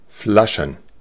Ääntäminen
Ääntäminen Tuntematon aksentti: IPA: /ˈflaʃn̩/ IPA: /ˈflaʃən/ Haettu sana löytyi näillä lähdekielillä: saksa Käännös Substantiivit 1. bottles Flaschen on sanan Flasche monikko.